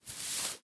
На этой странице собраны разнообразные звуки поворота головы – от легкого шелеста до выраженного хруста.
Звуки поворота головы: резкий разворот с длинными волосами